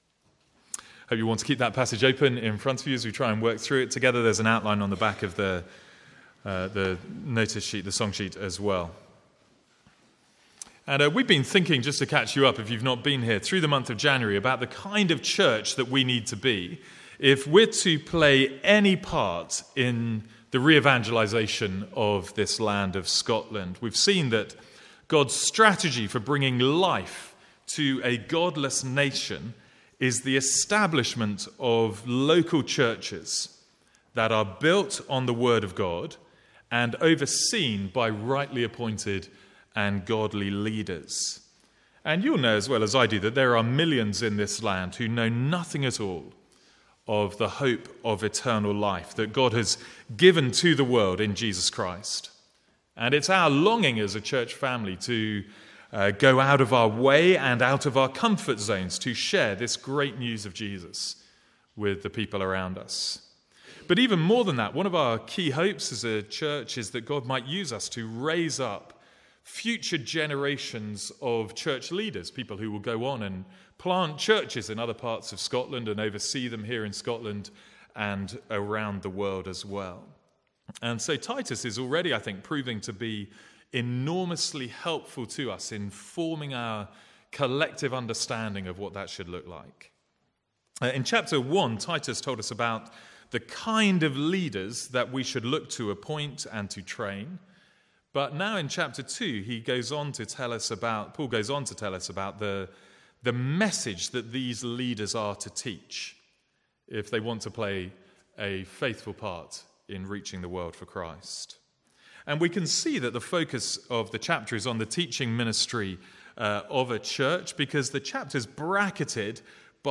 Sermons | St Andrews Free Church
From the Sunday morning series in Titus.